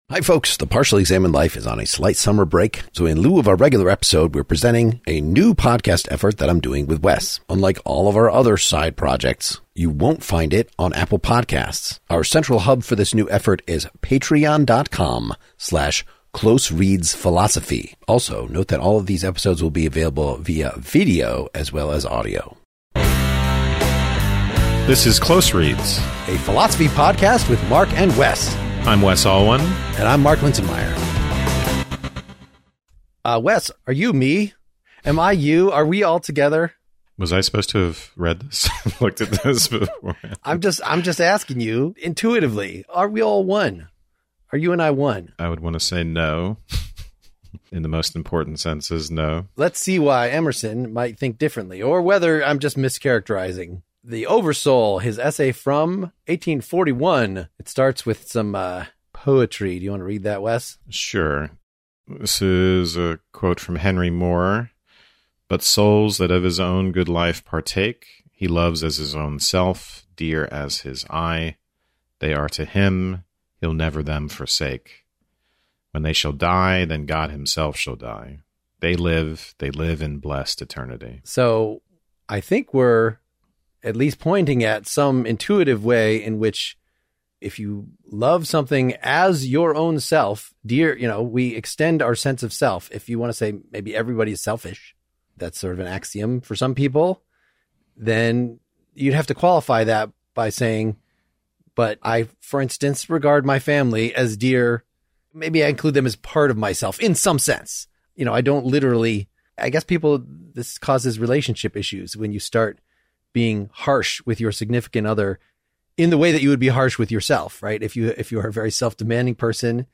PEL's most verbose hosts
begin unraveling this puzzling claim by reading Ralph Waldo Emerson's 1841 essay "The Over-Soul" and explaining it line-by-line.